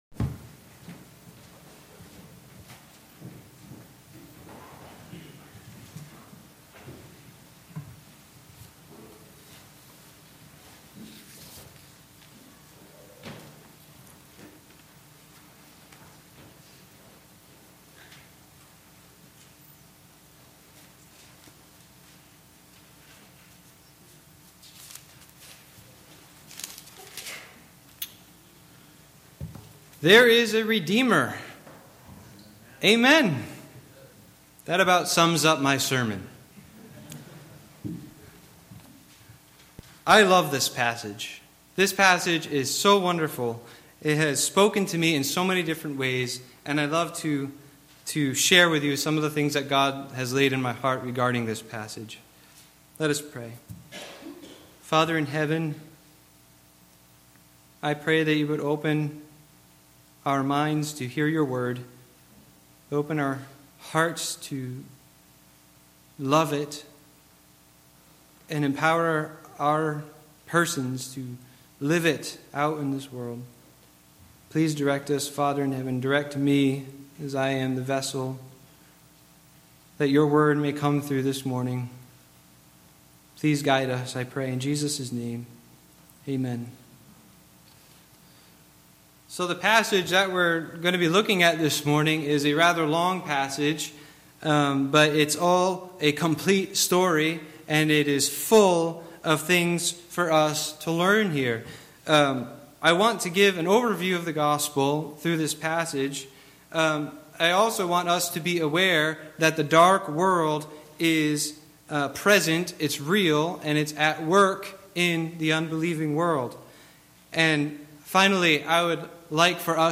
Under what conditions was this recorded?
Passage: Mark 5:1-20 Service Type: Sunday Morning Worship Topics